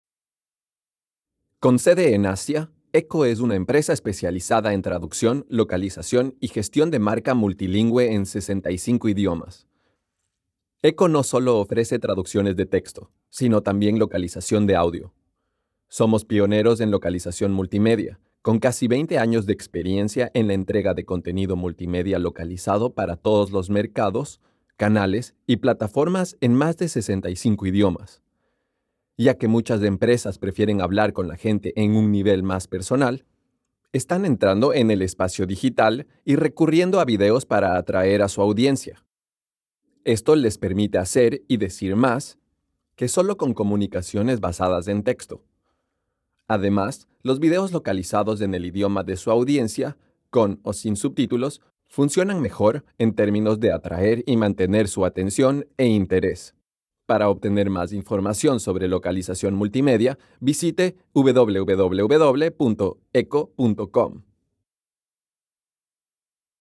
EQHO provides multi-language solutions from its in-house recording facilities
Spanish Male
NARRATION